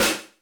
RX5 SNARE.wav